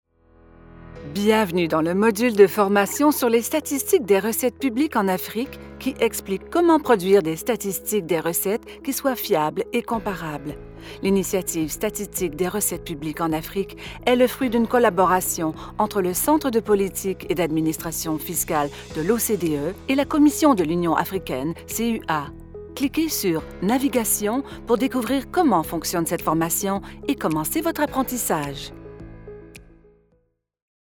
Apprentissage en ligne
-Neumann TLM 103 Microphone
-Professional sound isolation recording booth